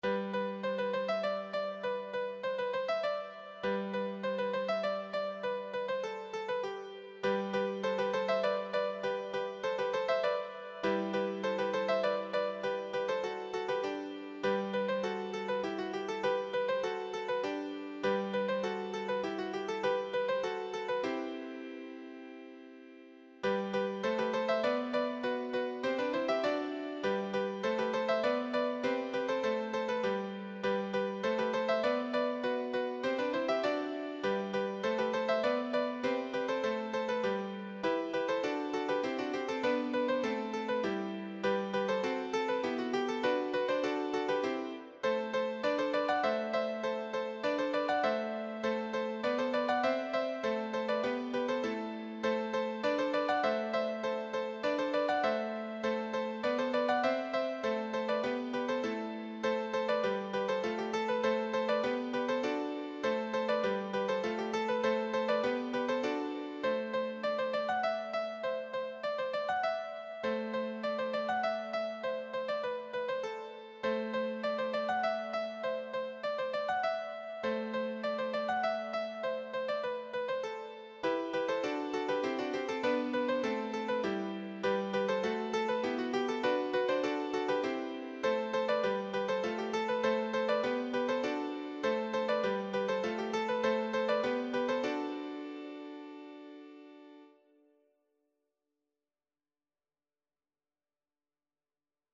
Arrangiert als Schottisch oder An Dro
Tonumfang G-E2, G-Dur/A-Moll
midi Tonbeispiel Klavier